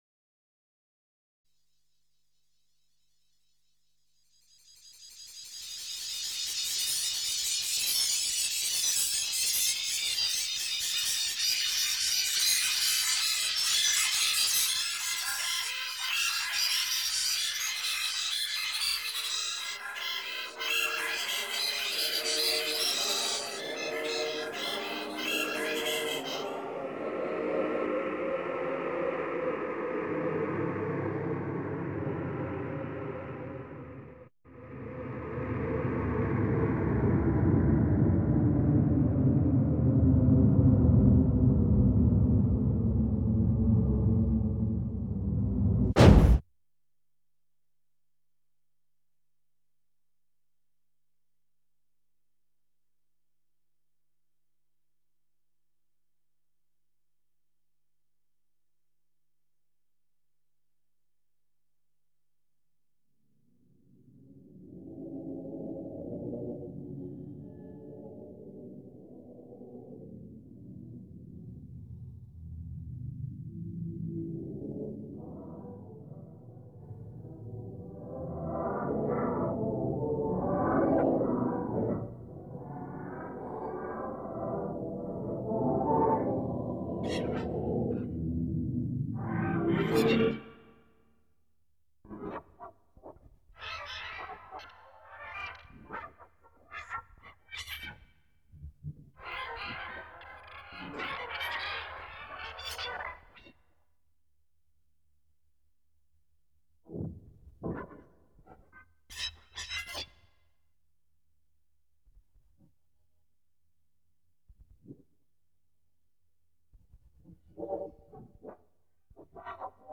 4-channel electroacoustic composition
studio: Klang Projekte Weimar